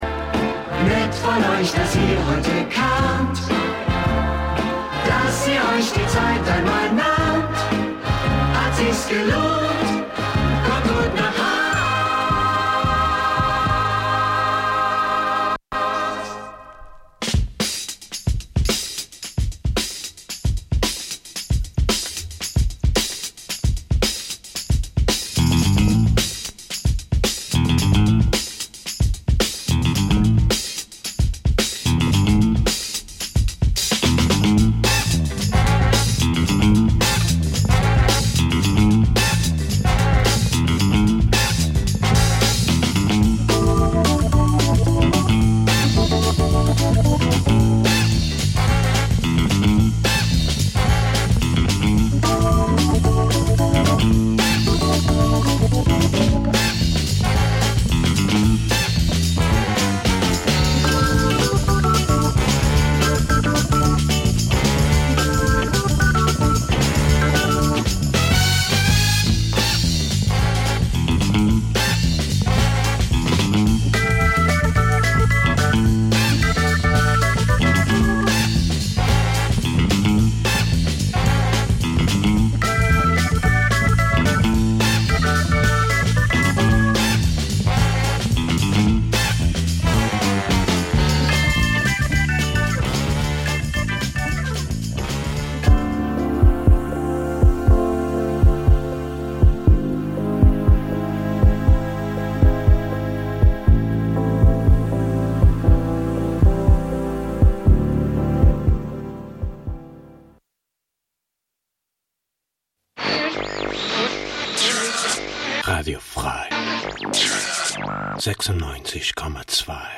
set